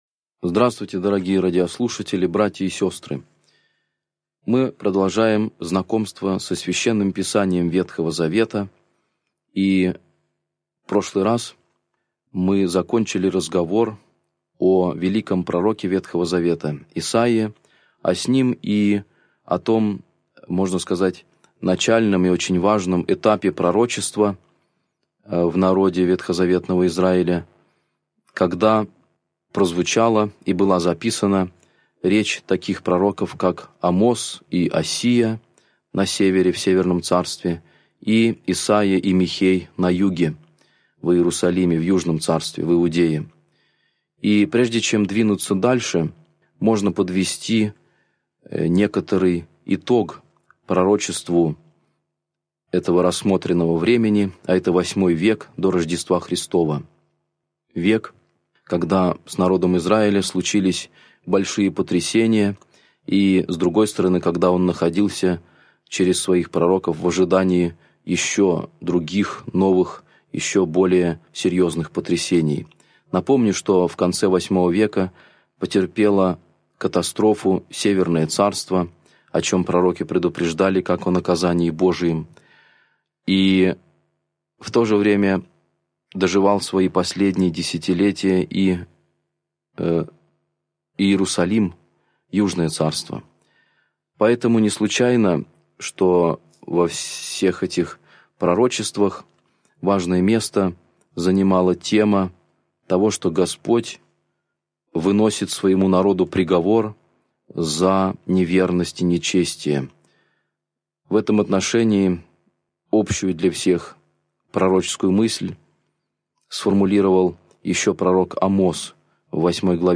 Аудиокнига Лекция 15. Книга Царств | Библиотека аудиокниг